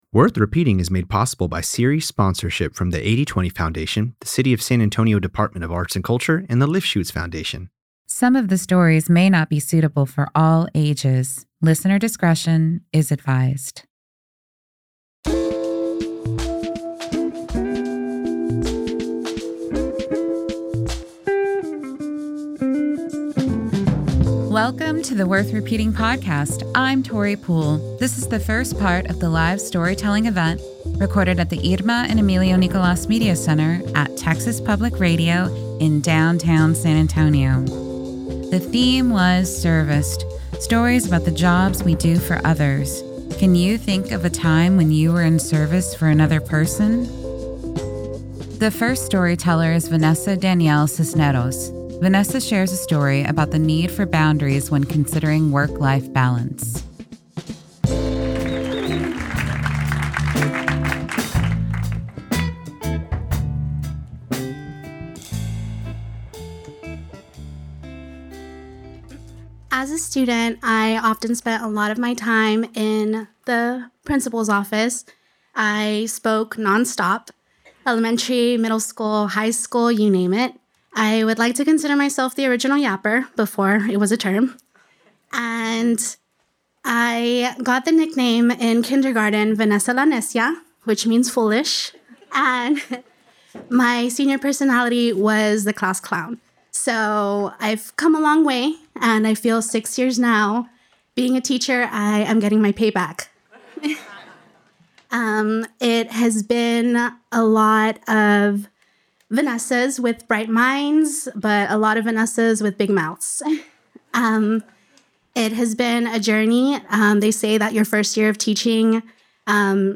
In this episode of the Worth Repeating podcast, storytellers share stories on the theme, Serviced.
Play Rate Listened List Bookmark Get this podcast via API From The Podcast Real stories told by your neighbors and friends in San Antonio.